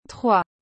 Com uma pronúncia charmosa, tipo “truá”, essa palavrinha parece simples, mas carrega muito mais do que um número.
Como pronunciar trois corretamente?
• O “tr” é mais suave do que em português. Nada de “trrrês”!
• O “is” final não é bem um “is”, e sim um som nasal sutil.
trois.mp3